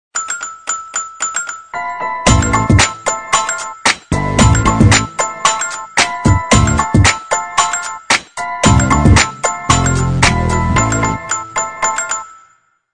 Tono animado para darle ritmo a tu movil.